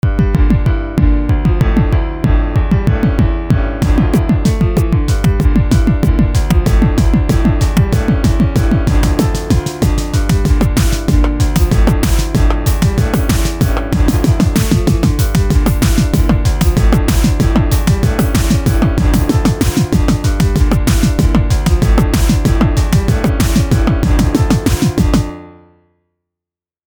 two guitar.mp3